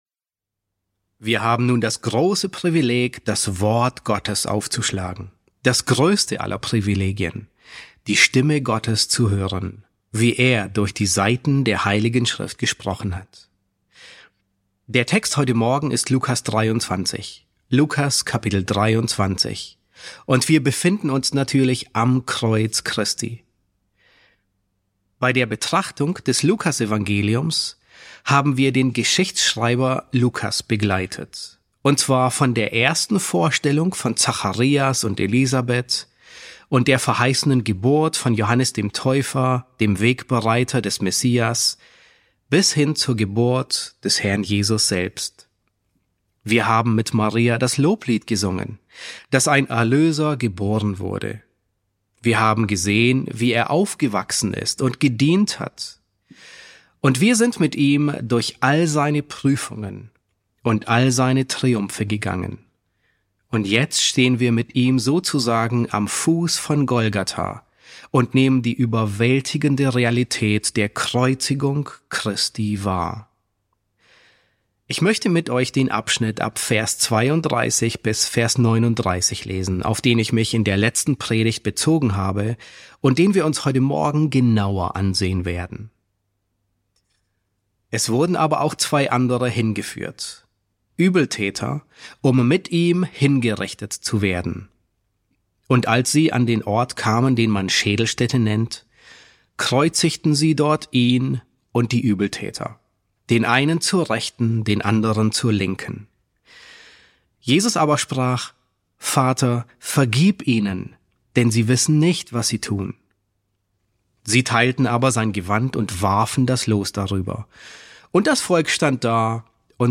E2 S1 | Der gekreuzigte König: Der Kontrast auf Golgatha ~ John MacArthur Predigten auf Deutsch Podcast